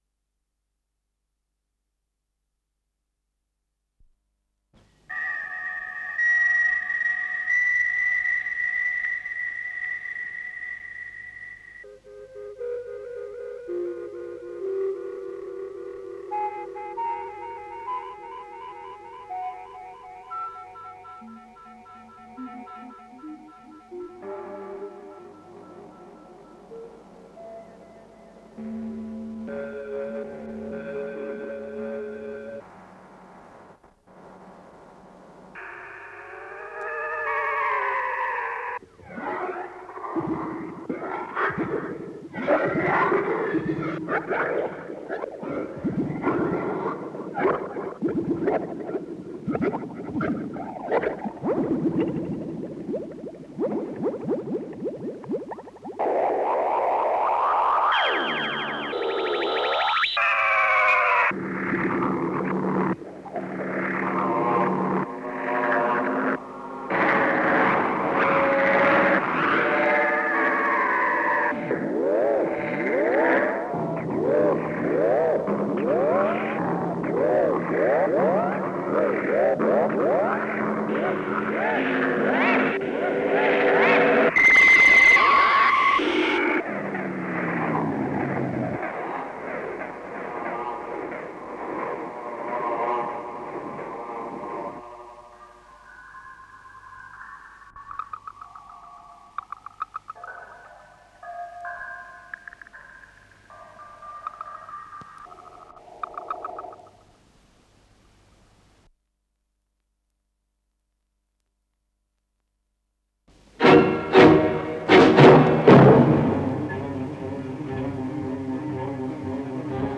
Н-774 — Электронные шумы — Ретро-архив Аудио